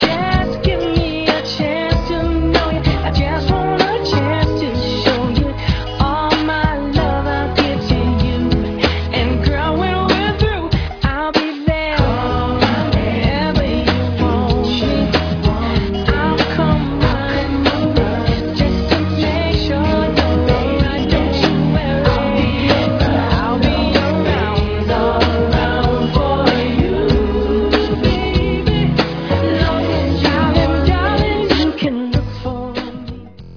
background vocals, synthesizers and drum programming